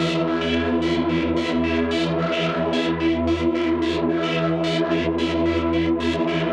Index of /musicradar/dystopian-drone-samples/Tempo Loops/110bpm
DD_TempoDroneC_110-E.wav